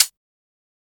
kits/OZ/Closed Hats/OZ-Hihat 11.wav at main
OZ-Hihat 11.wav